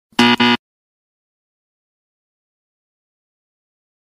Звуки отмены для монтажа
Звук неправильного ответа (Сто к одному)